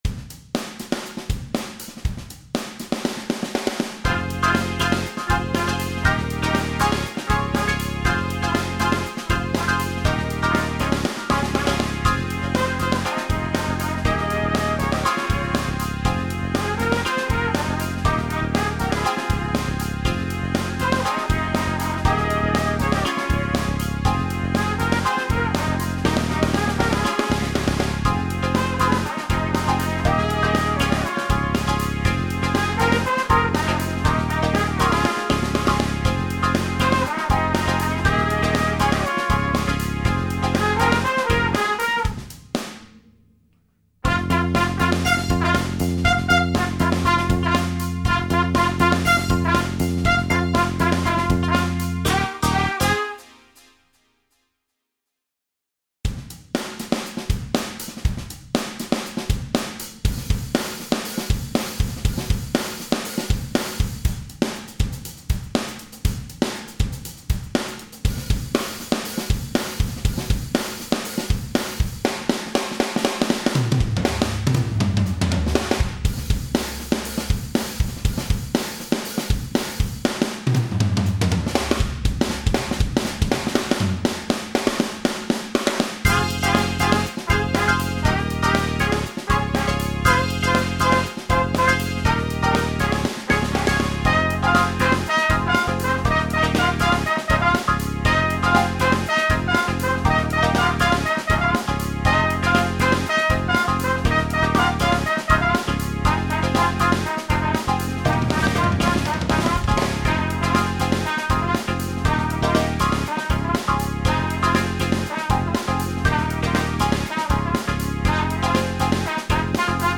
BRASS ENSEMBLE • ACCOMPAGNAMENTO BASE MP3
Trombone 1 - 2 - 3